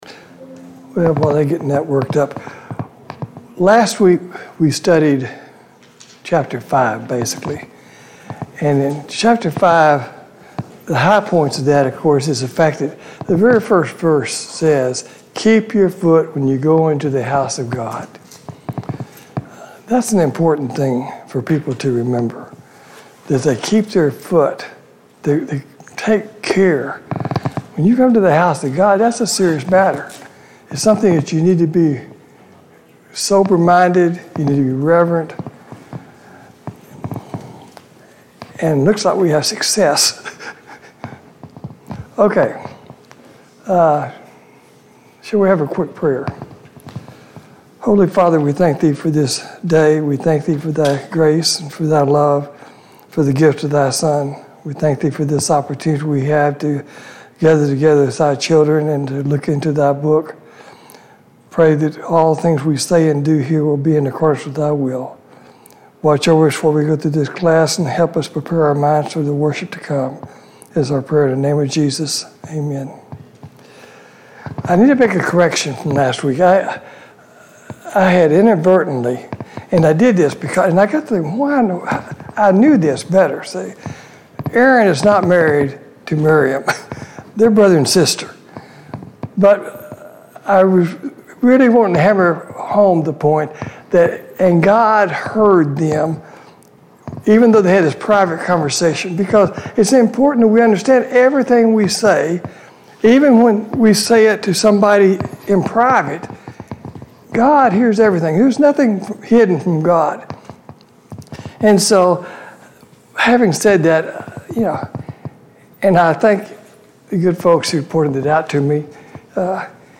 Sunday Morning Bible Class « 38.